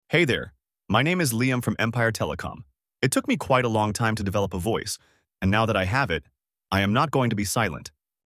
Empire Telecom offers its customers free, professionally recorded auto-attendant greetings and voicemail messages.
Male